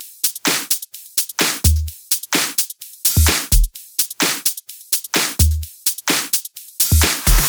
VDE 128BPM Dynamite Drums 2.wav